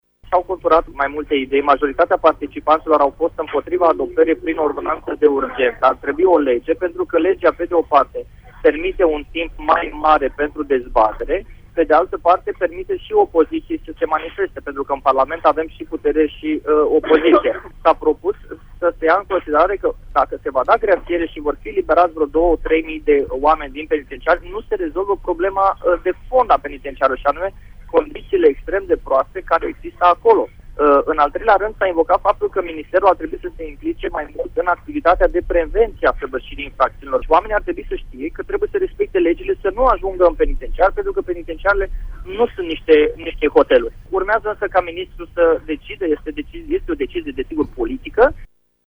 Cristi Danileț, membru al Asociației ”Vedem Just”, prezent la dezbaterile de la Ministerul Justiției a venit cu precizări despre conținutul ordonanțelor în cadrul emisiunii Pulsul Zilei de la Radio Tîrgu-Mureș: